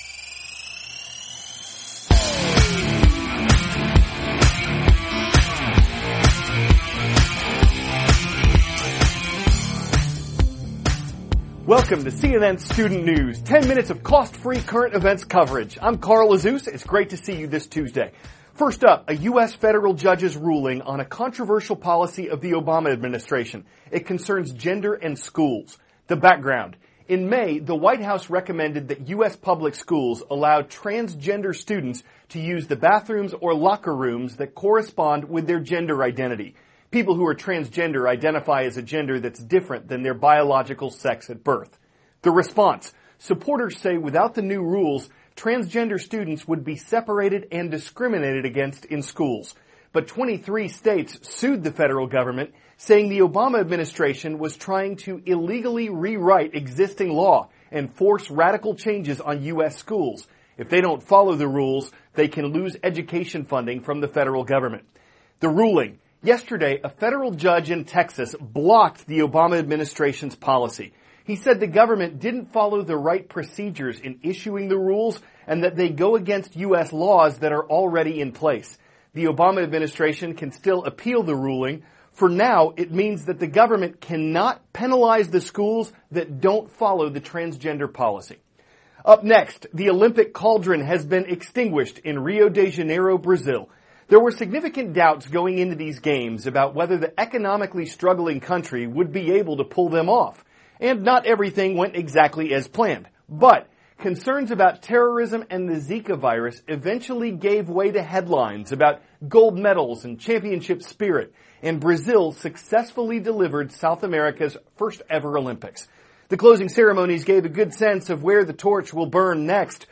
News